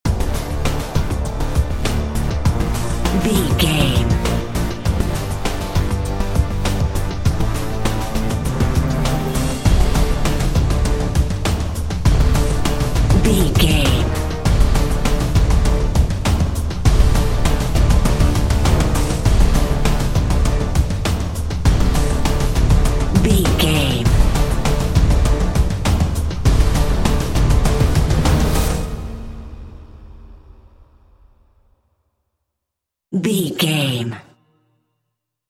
Epic / Action
Aeolian/Minor
drum machine
synthesiser
brass
driving drum beat